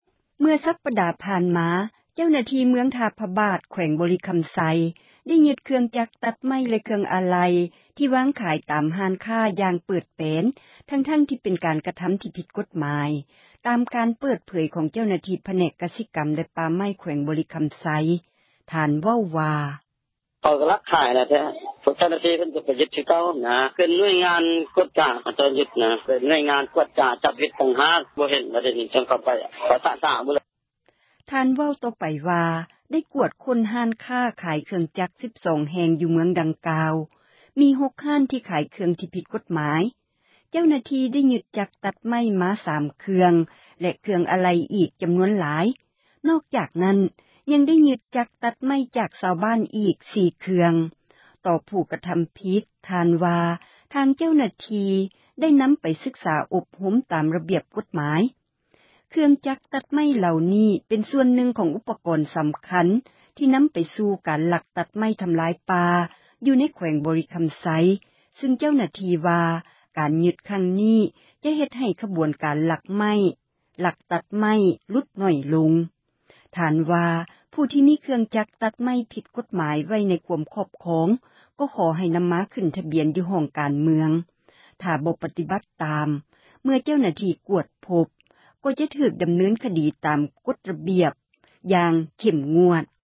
ເຄື່ອງຈັກຕັດໄມ້ ຖືກຍຶດ — ຂ່າວລາວ ວິທຍຸເອເຊັຽເສຣີ ພາສາລາວ